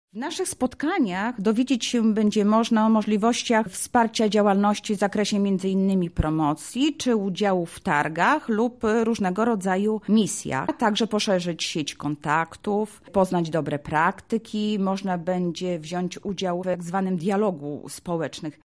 Mówi Anna Augustyniak, Sekretarz Województwa Lubelskiego